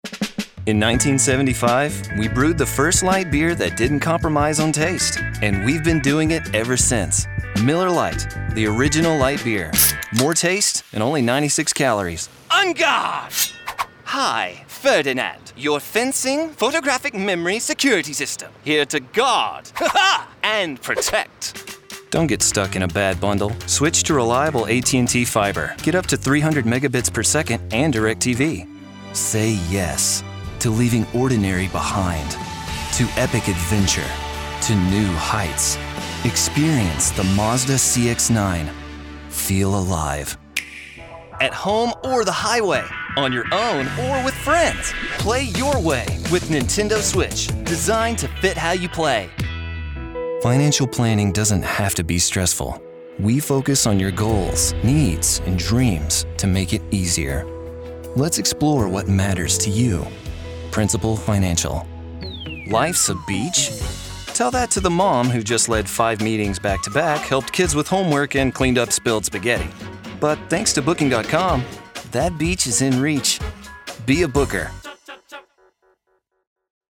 As a top-rated Voice Talent, I can provide outstanding US accented Voice Overs for commercials, promos, animations, and narrations for clients all around the globe.
I produce all of my material through the use of my Source-Connect enabled & acoustically treated home studio based in Chattanooga, TN.
Microphones: Neumann TLM 103 & Sennheiser MKH 416 Interface: UA Apollo Twin X Normally speaking in a slight southern accent, I can produce products in any form of English. My strong-suit is friendly authentic enthusiasm.
Location: Chattanooga, TN, USA Languages: english 123 Accents: standard us Voice Filters: VOICEOVER GENRE commercial promos